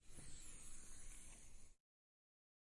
液压臂
描述：椅子的短音频文件缓慢释放，加工成听起来像叉车或各种机械中使用的液压臂。
Tag: 气闸 airrelease 压力 空气 液压 系统 液压系统 压力 OWI 气动 机械